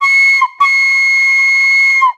Train_v3_wav.wav